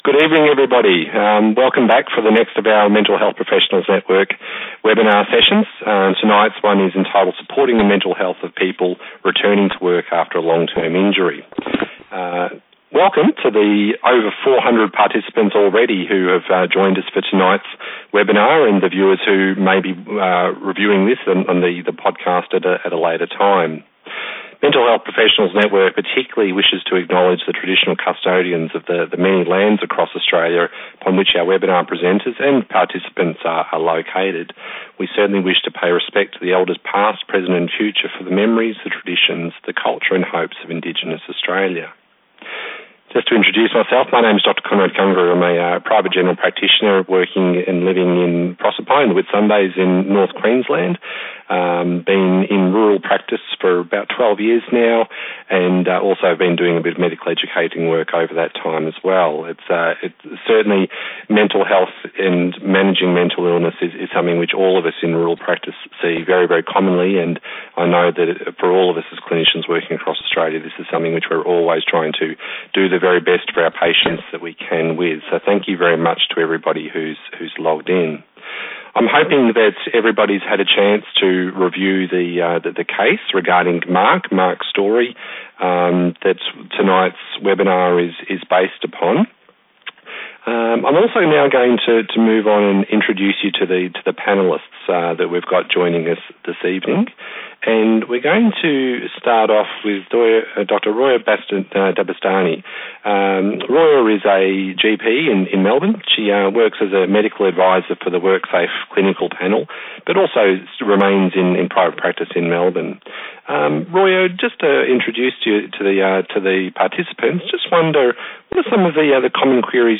Join our interdisciplinary panel of experts for a discussion on how people with long term absences from the workplace due to an injury may be supported to return to work.